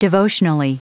Prononciation du mot devotionally en anglais (fichier audio)